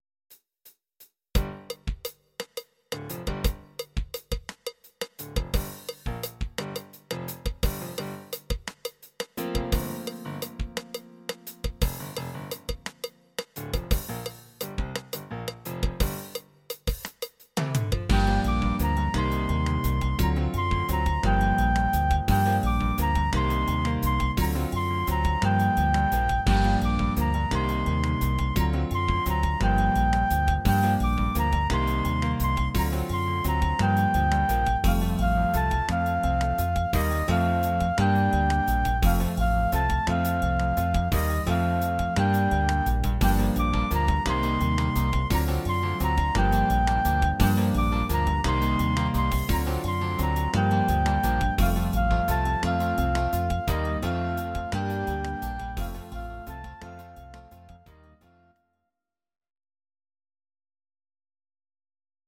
Audio Recordings based on Midi-files
Pop, Jazz/Big Band, 1970s